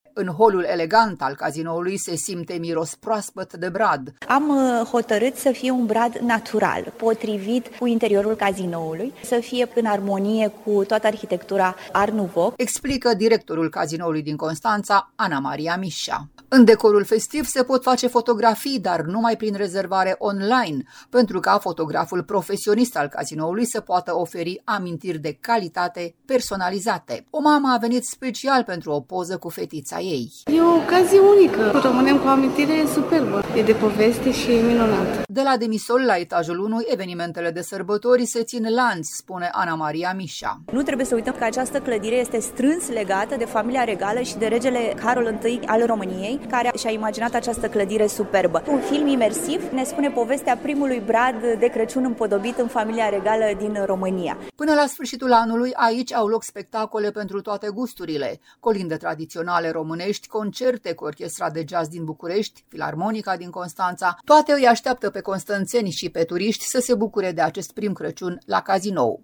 O mamă a venit special pentru o poză cu fetița ei: